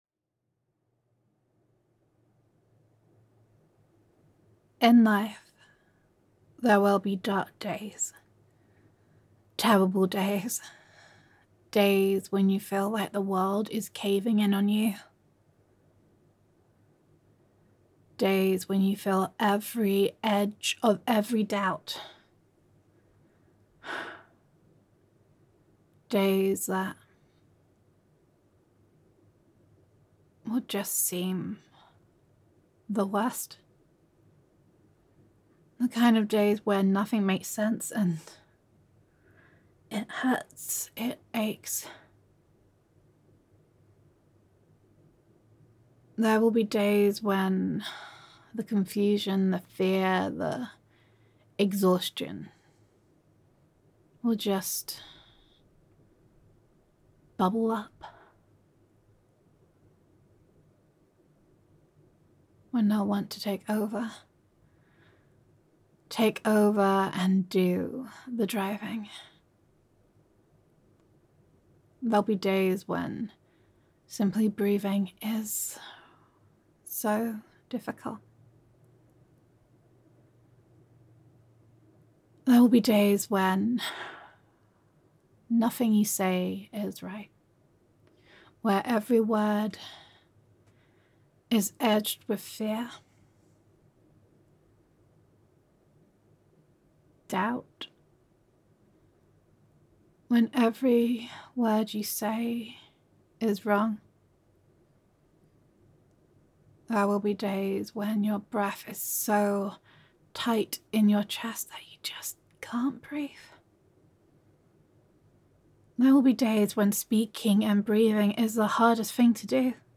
[F4A] Stronger Than You Know [Tender Reassurance][Girlfriend Roleplay][Comfort][Weathering the Storm][Gender Neutral][Comfort for Bad Days]